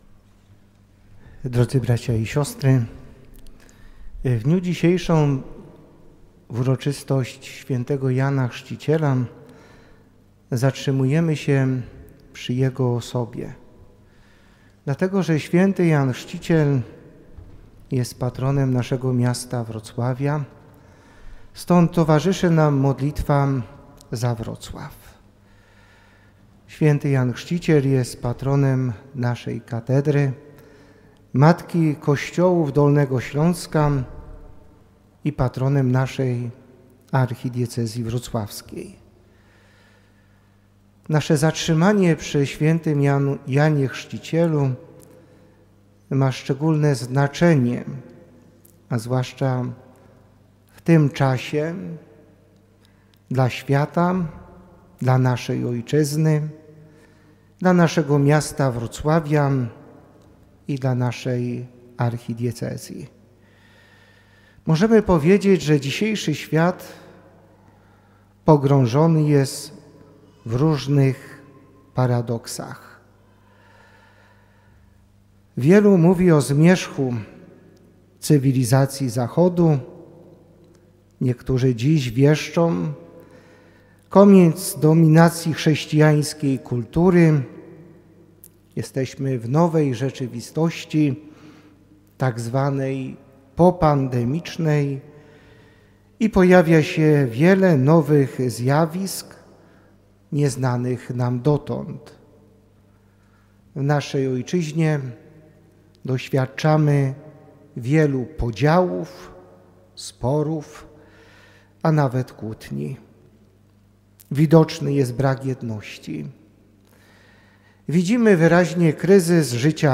Msza Św. sprawowana była w intencji wrocławian, w uroczystość św. Jana Chrzciciela – patrona Wrocławia. Mszy przewodniczył ojciec bp Jacek Kiciński, uczestniczył w niej wiceprezydent Jakub Mazur i radni miejscy oraz wierni. Chcemy odnowy Kościoła, naszego serca i na tle tych wydarzeń patrzymy na postać Jana Chrzciciela – mówił ojciec biskup J. Kiciński Posłuchaj homilii: